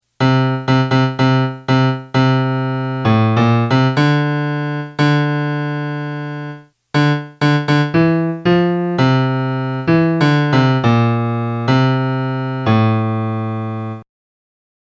maremma-melody.mp3